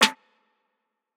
[Snr] n Perc.wav